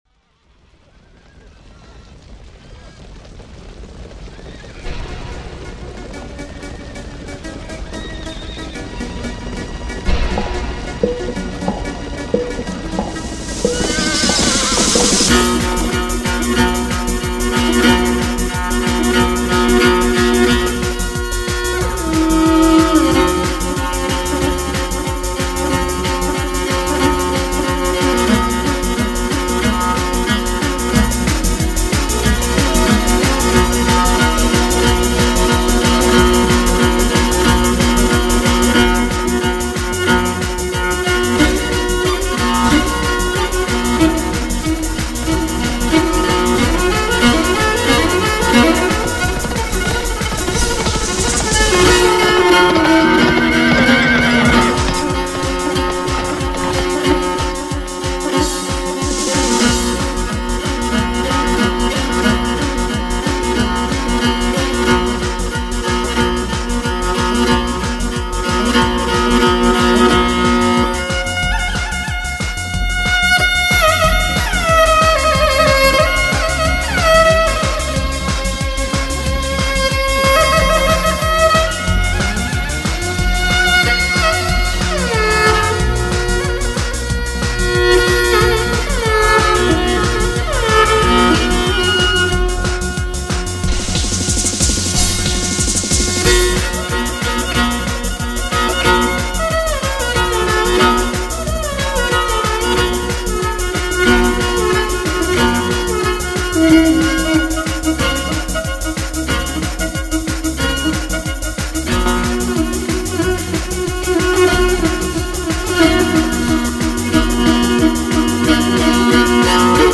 独具草原气息的民族文化，极富突破性的流行风格。
馬頭琴是具有濃郁蒙古族民間特色和悠久歷史的一種拉弦樂器，因琴桿上端雕有馬頭而得名。
改進後的馬頭琴，一改以往低沉、哀婉的音色，音域寬闊，音色乾淨、飽滿、靚麗、悠揚、渾厚，具有極為豐富的藝術表現力。